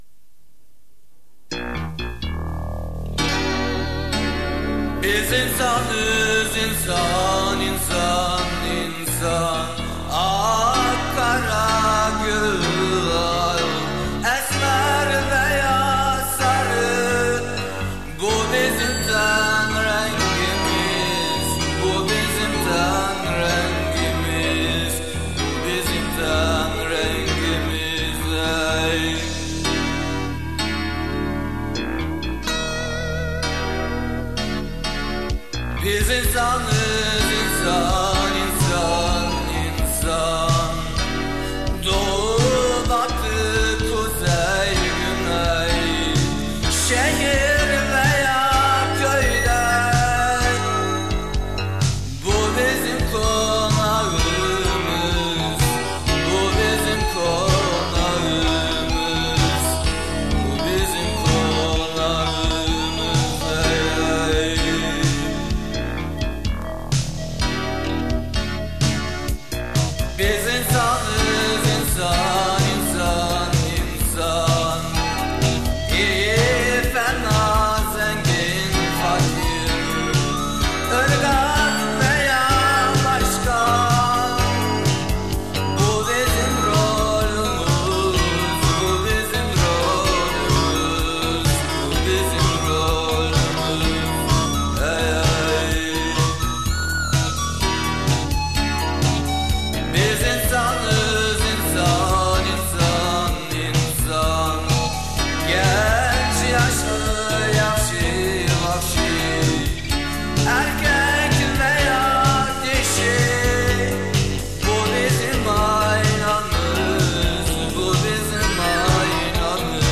Pop-Rock-Şiirsel